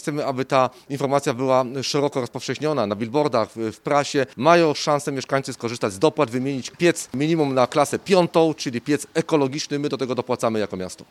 – W poprzednim roku czujniki finansowane były dzięki puli radnych – Mówi Janusz Rewers, miejski radny z Koalicji Obywatelskiej: